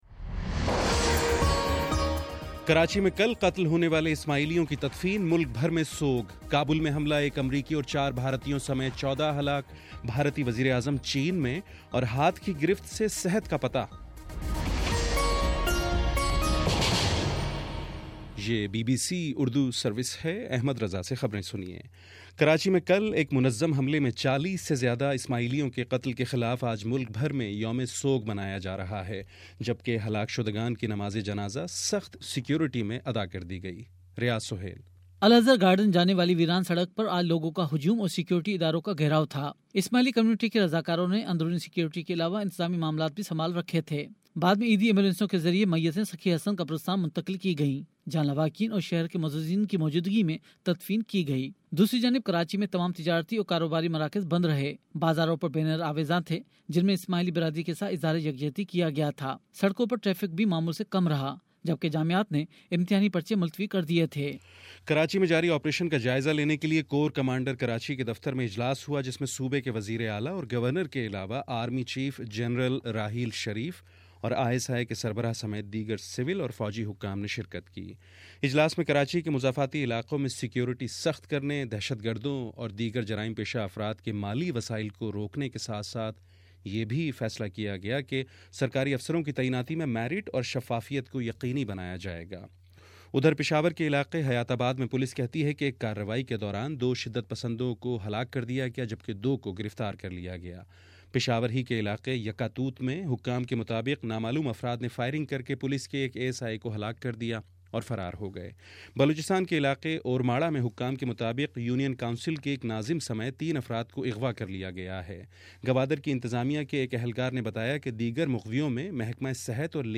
مئی 14: شام چھ بجے کا نیوز بُلیٹن